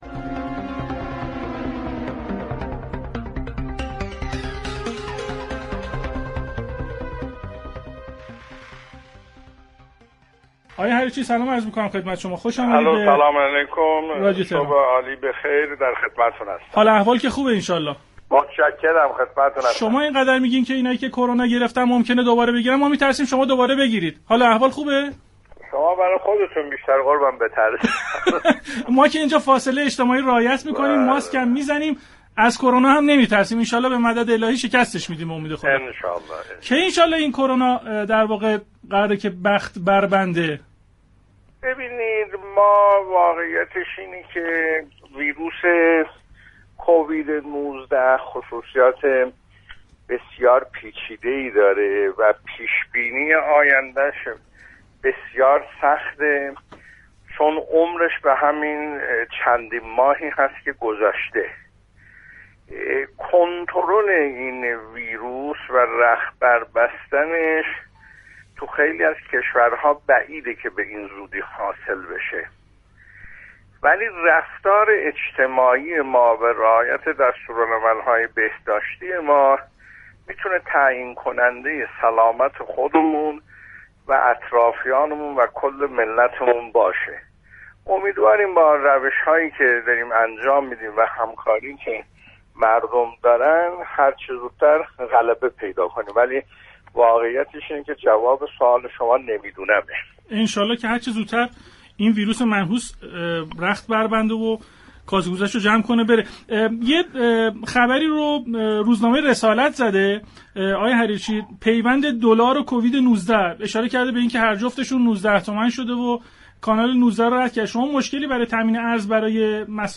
ایرج حریرچی در گفتگو با پارك شهر، در نخستین روز تابستان گفت: رفتار اجتماعی ما می‌تواند تعیین كننده‌ی سلامت خود و اطرافیانمان باشد.